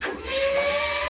SERVO SE08.wav